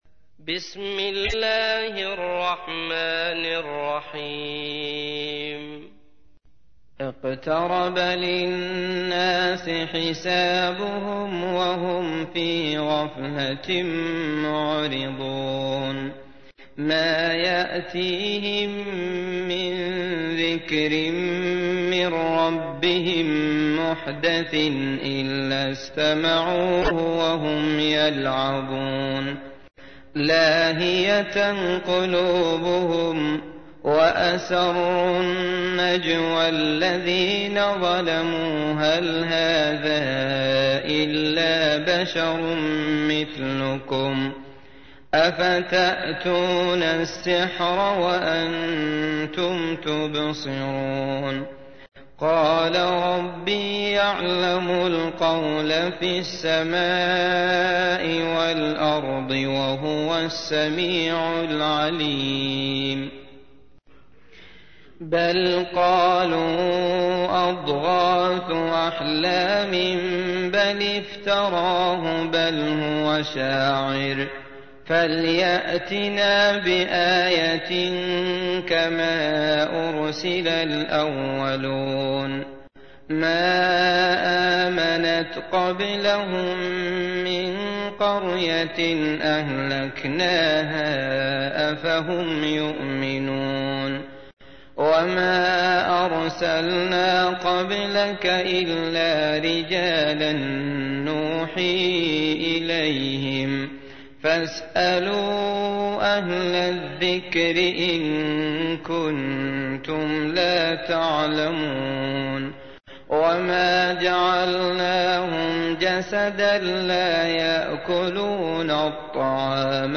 تحميل : 21. سورة الأنبياء / القارئ عبد الله المطرود / القرآن الكريم / موقع يا حسين